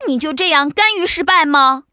文件 文件历史 文件用途 全域文件用途 Choboong_fw_02.ogg （Ogg Vorbis声音文件，长度0.0秒，0 bps，文件大小：27 KB） 源地址:游戏语音 文件历史 点击某个日期/时间查看对应时刻的文件。